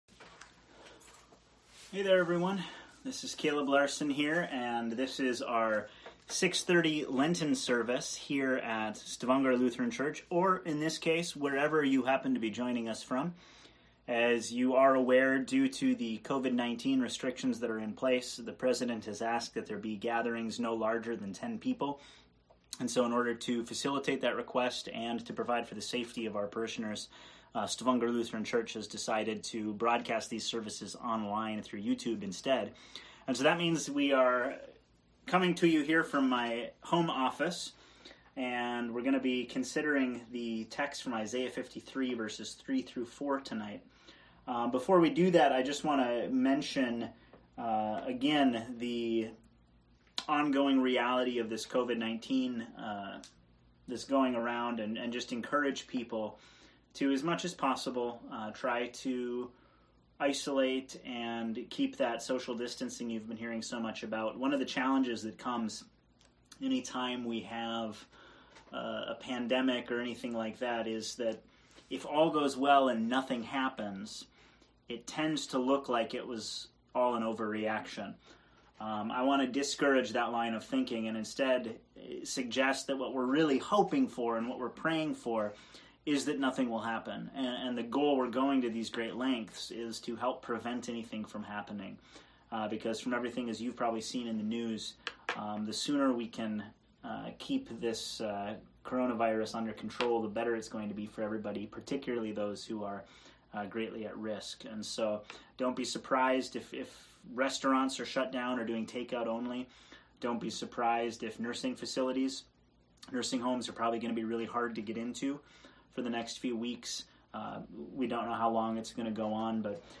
A message from the series "Lent."